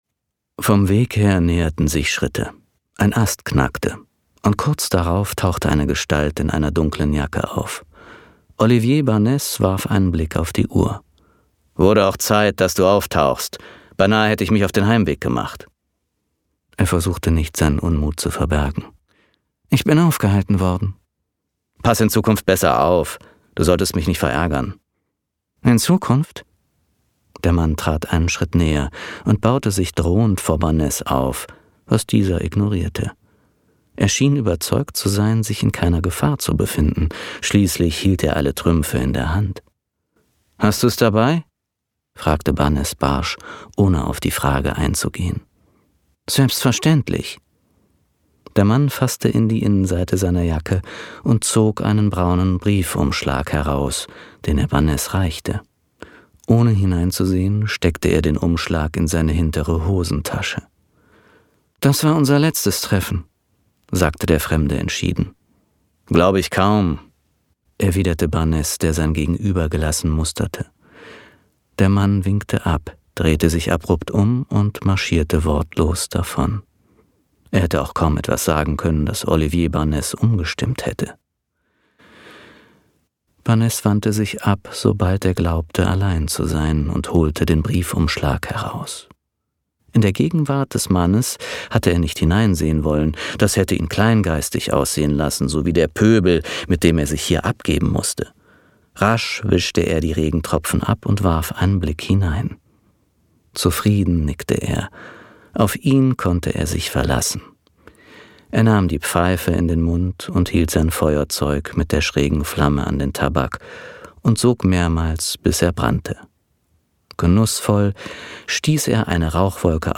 Hörbuch Krimi Der Tote im Wald – Auszug